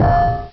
Звук лопающегося хрусталя